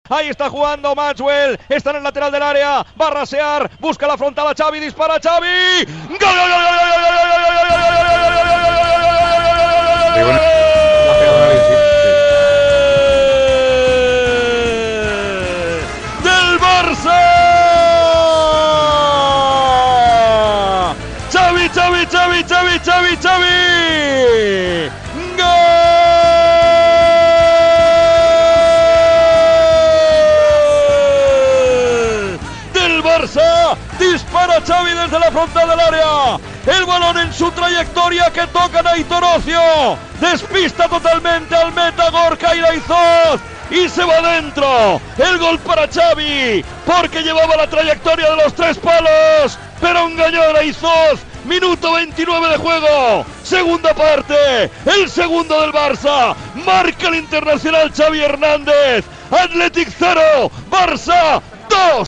Narració del gol de Xavi Hernàndez al partit Athletic Club -Futbol Club Barcelona ,reconstrucció de la jugada i resultat.
Esportiu